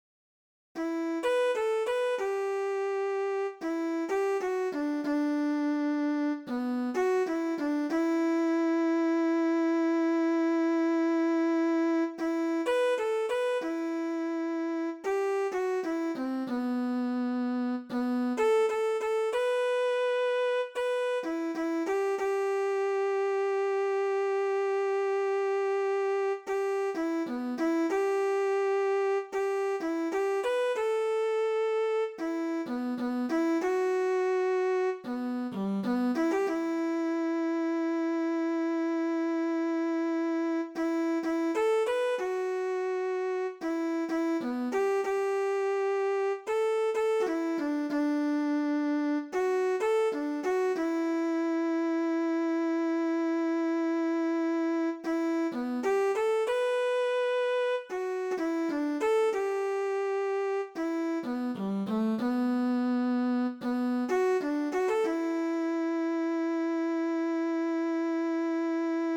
THIẾU NHI CA